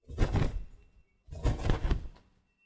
Cloth Moving Sound
household
Cloth Moving